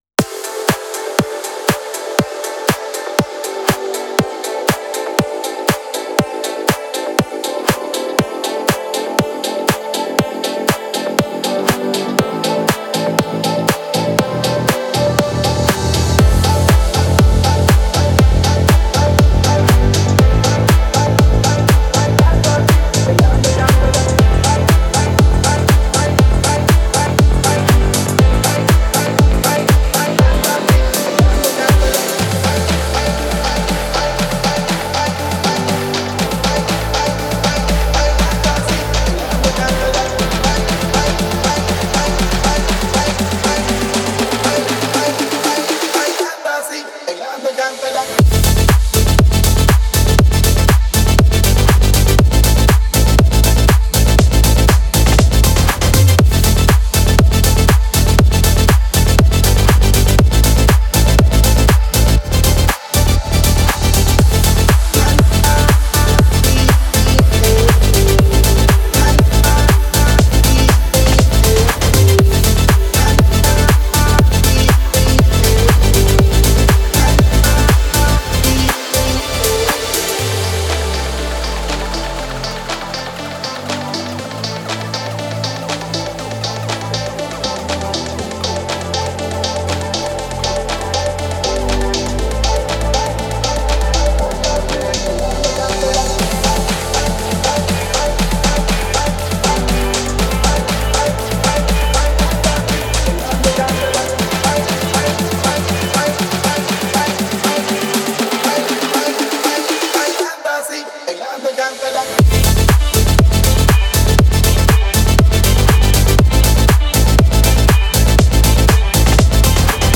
это зажигательная латинская поп-песня
С яркими мелодиями и ритмичными битами